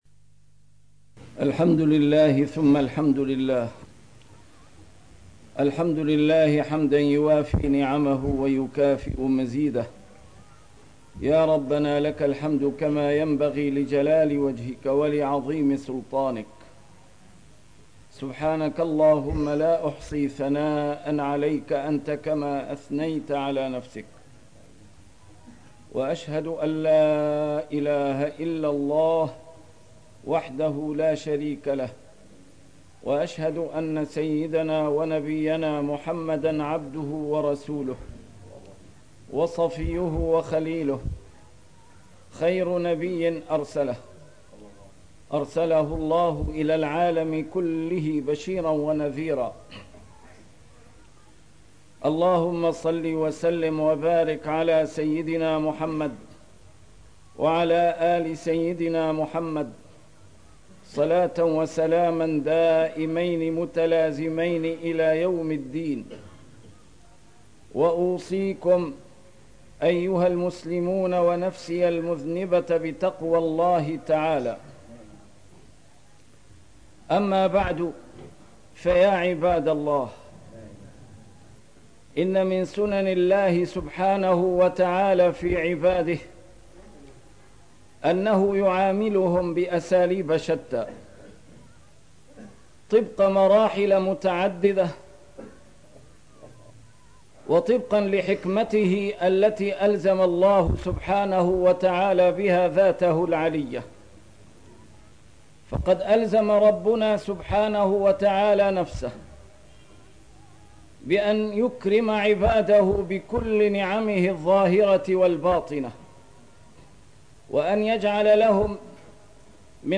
A MARTYR SCHOLAR: IMAM MUHAMMAD SAEED RAMADAN AL-BOUTI - الخطب - أخطر عقاب يعاقب به المعرضون